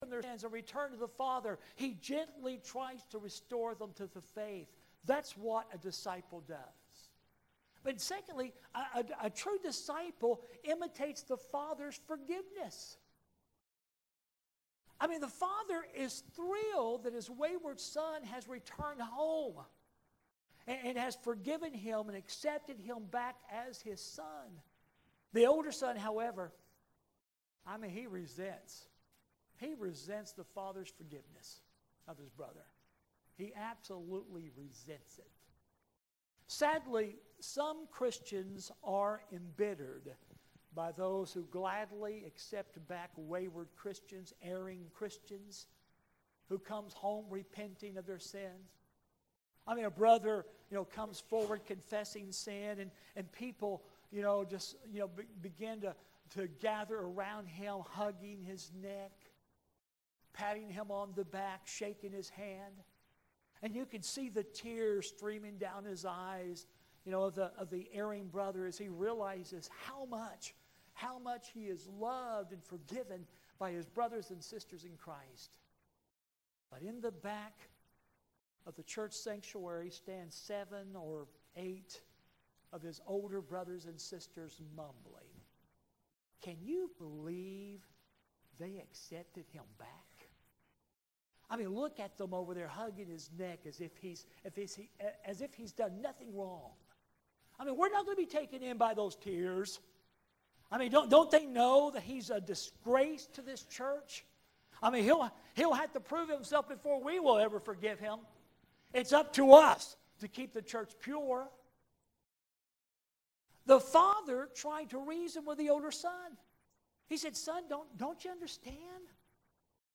SUNDAY LESSON, 11/2/25